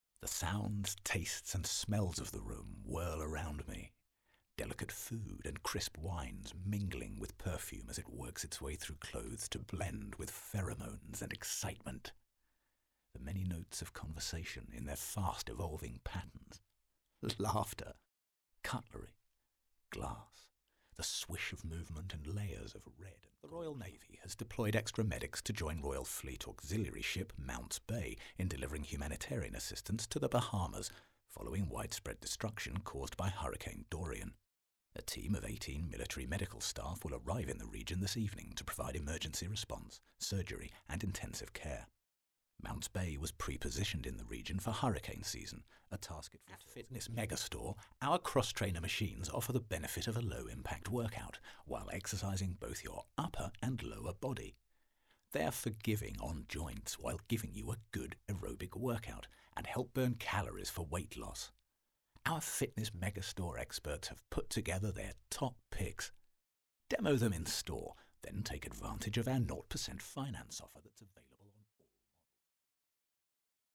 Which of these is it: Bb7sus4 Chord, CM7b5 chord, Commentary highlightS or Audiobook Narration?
Audiobook Narration